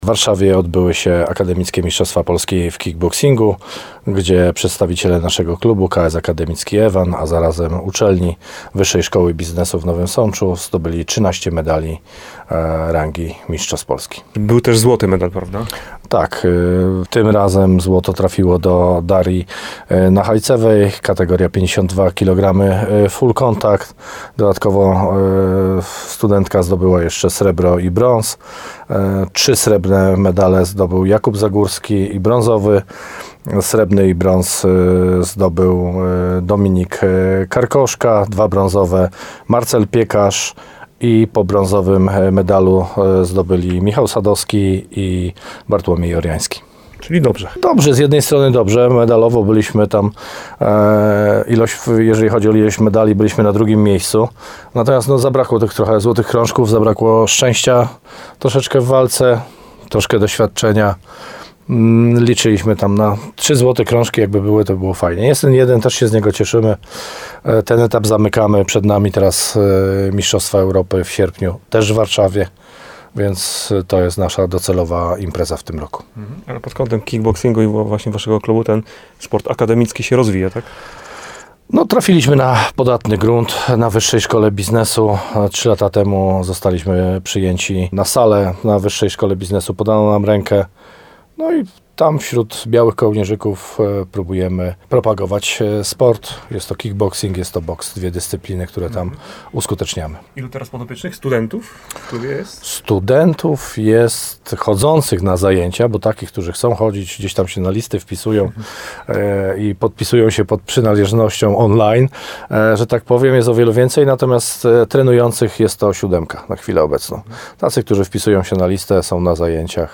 Worek medali akademików z Nowego Sącza [ROZMOWA]